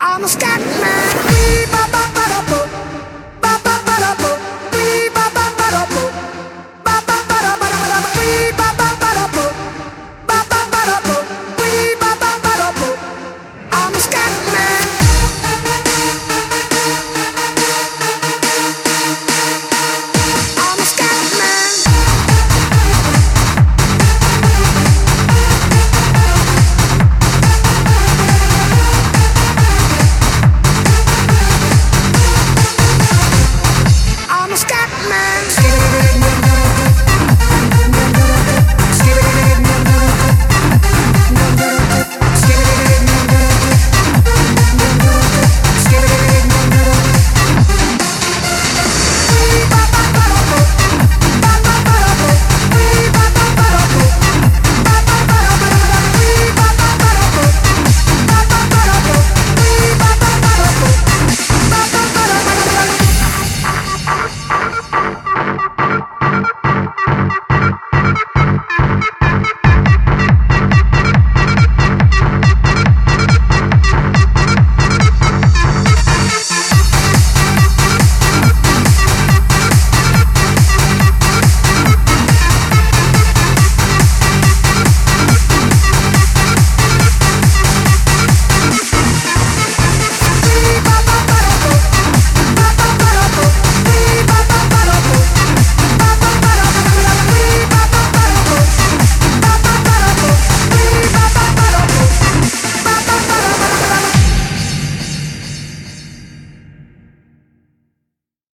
BPM140
It's... it's trance scatting, ok?